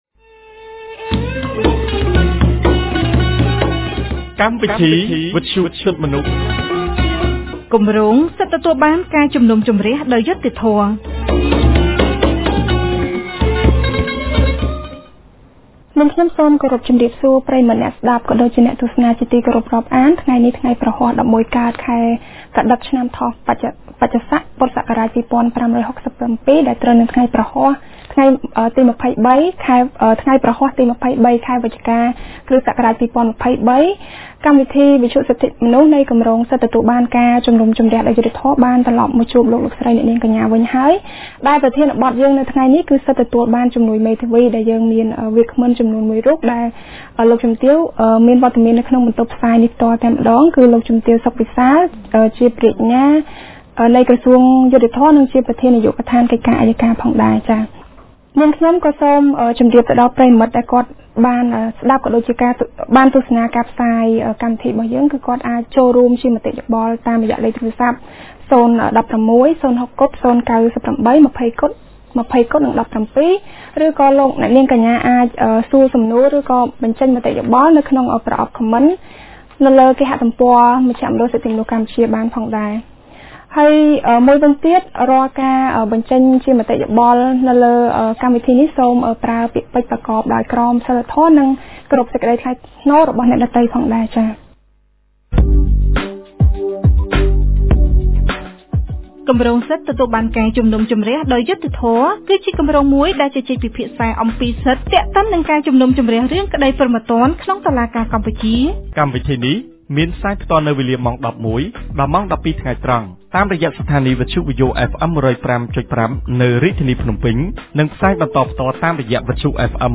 កាលពីថ្ងៃព្រហស្បតិ៍ ទី២៣ ខែវិច្ឆិកា ឆ្នាំ២០២៣ ចាប់ពីវេលាម៉ោង១១:០០ ដល់ម៉ោង ១២:០០ថ្ងៃត្រង់ គម្រាងសិទ្ធិទទួលបានការជំនុំជម្រះដោយយុត្តិធម៌នៃមជ្ឈមណ្ឌលសិទ្ធិមនុស្សកម្ពុជា បានរៀបចំកម្មវិធីវិទ្យុក្រោមប្រធានបទស្តីពី សិទ្ធិទទួលបានជំនួយមេធាវី វាគ្មិនកិត្តិយសចូលរួមកិច្ចពិភាក្សាក្នុងកម្មវិធីនេះគឺ លោកជំទាវ សុខ វិសាល ព្រះរាជអាជ្ញាក្រសួងយុត្តិធម៌ និងជាប្រធាននាយកដ្ឋានកិច្ចការអយ្យការ។